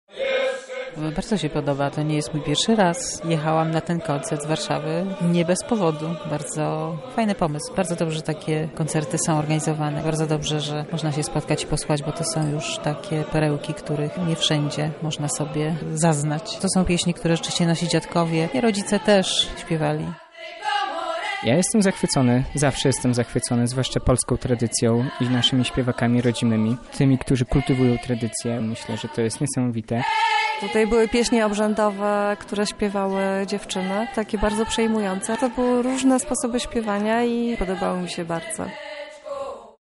Najstarsze Pieśni Europy to festiwal łączący na jednej scenie pieśni i tańce starego kontynentu.